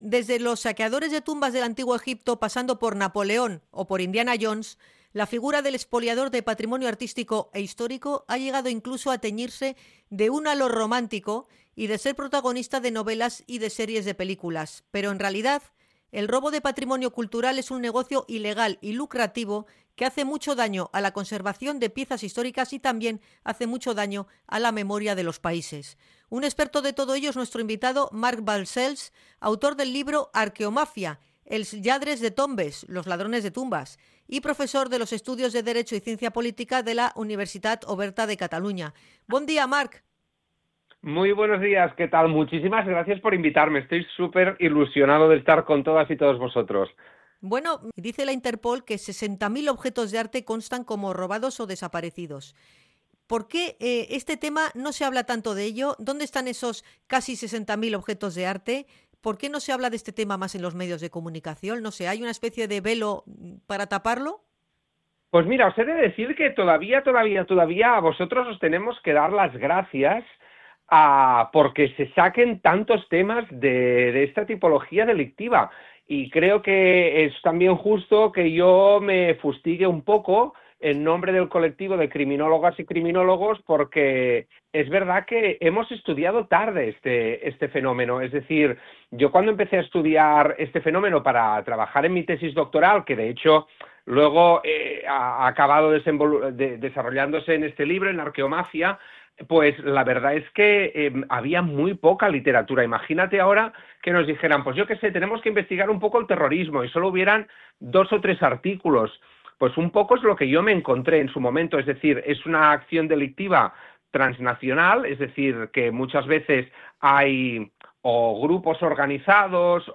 Entrevista a criminólogo sobre los expoliadores de patrimonio